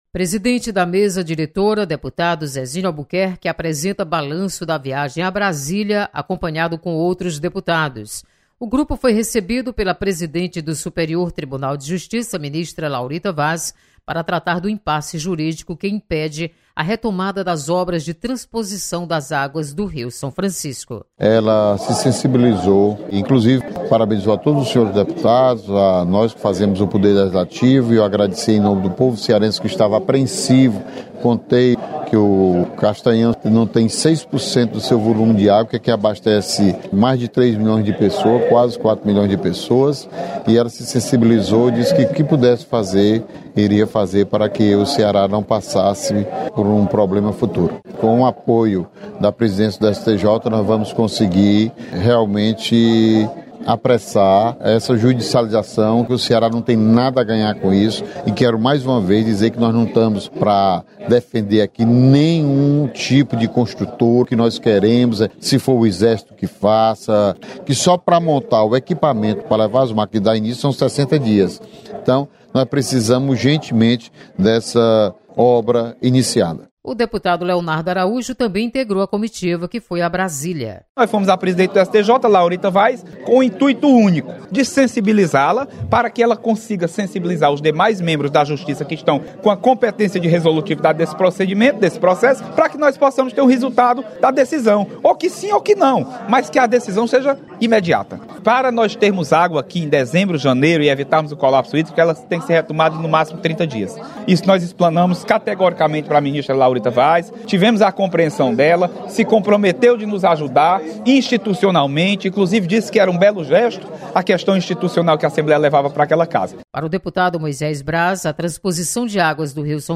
Deputado Zezinho Albuquerque comenta sobre reunião com presidente do STJ.